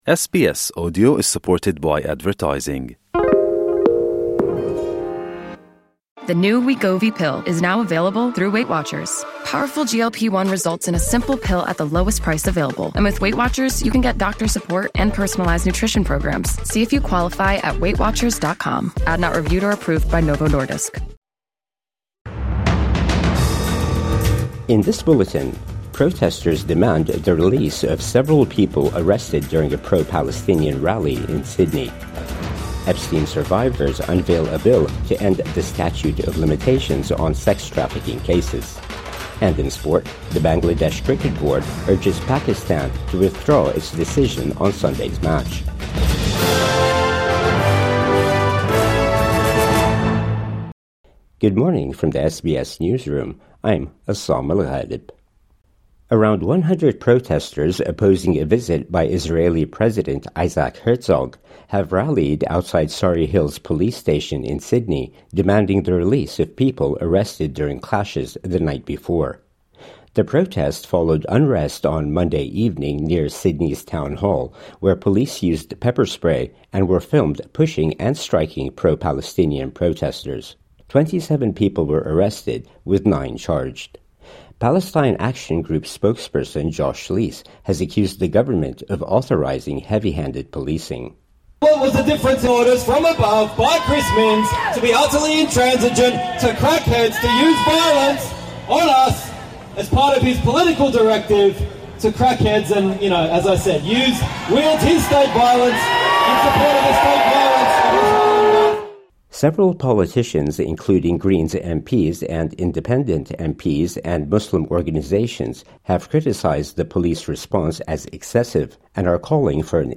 Protest held at Surry Hills police station over officers' tactics | Morning News Bulletin 11 February 2026